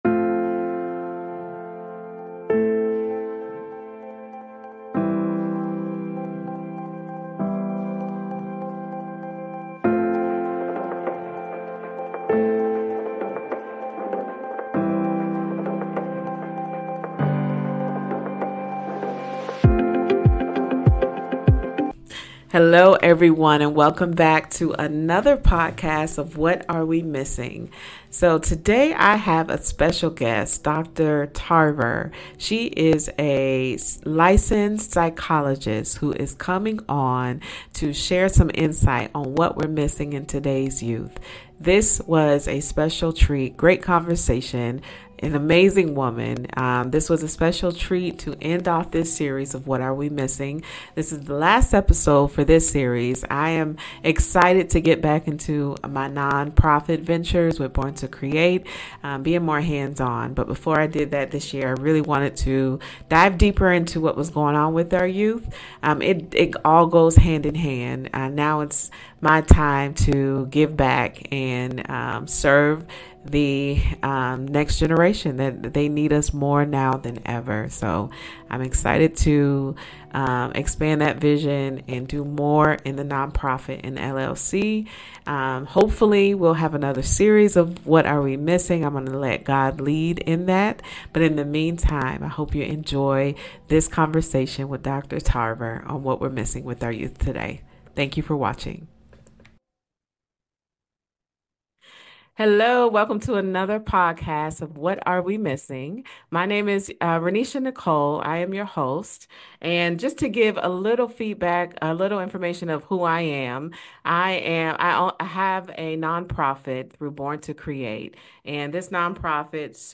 We’ll explore the warning signs we often overlook, how to better support our children emotionally, and the role we all play in fostering their well-being. This is a conversation every parent, teacher, and mentor needs to hear.